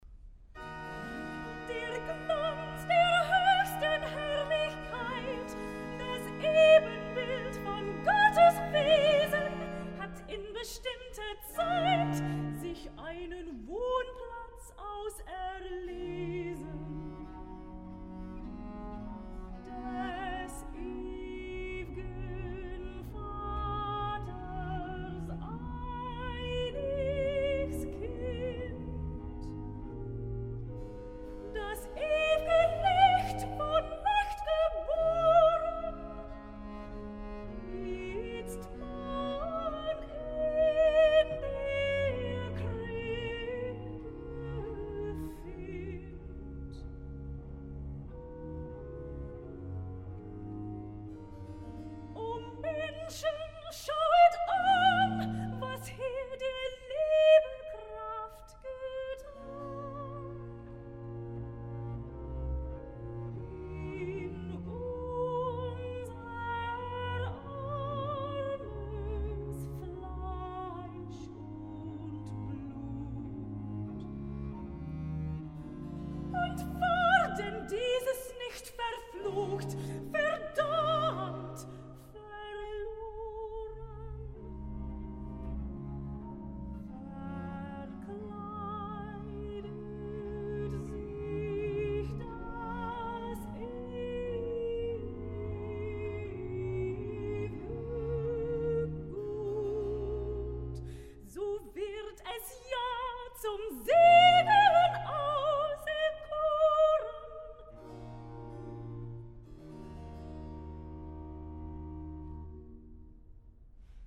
Cantata